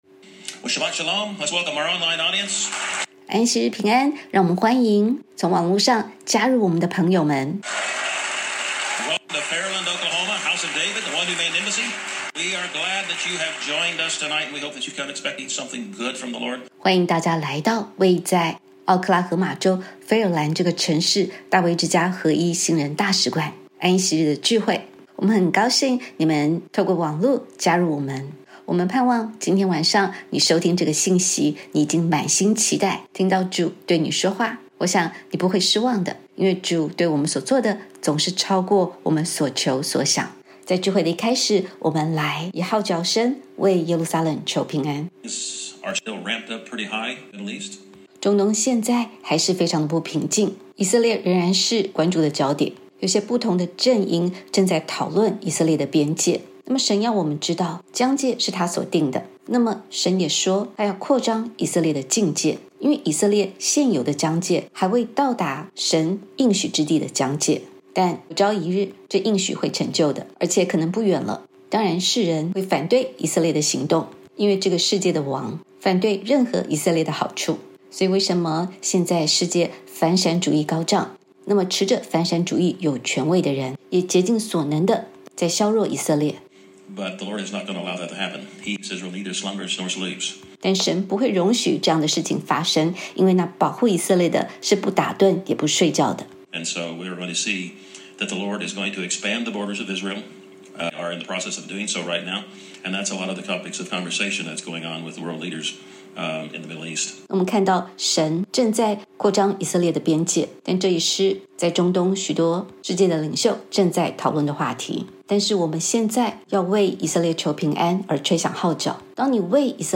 以下是语音信息的大纲： 一、埃波月特质 1.双重时序 犹太历5月（灵历）或11月（民历），分前后两阶段： 前半月：公义审判，呼召省察（赛40:1-2；民14:28-29）。